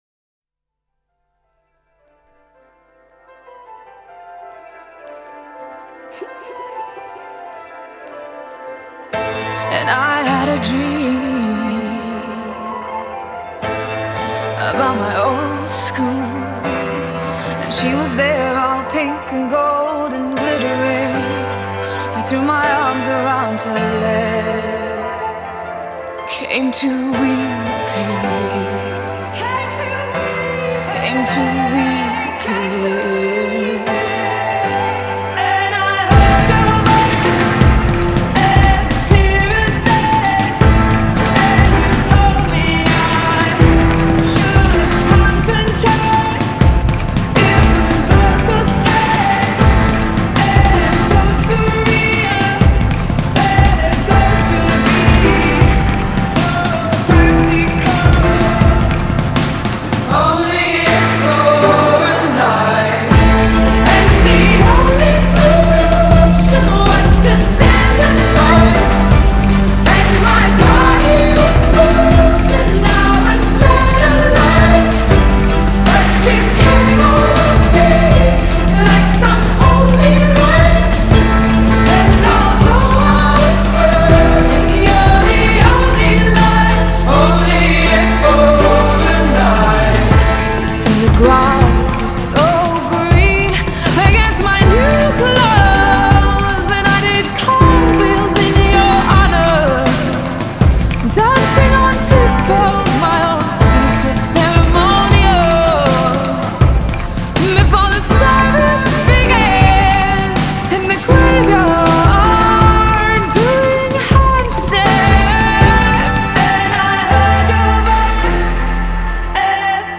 Genre: Indie, Alternative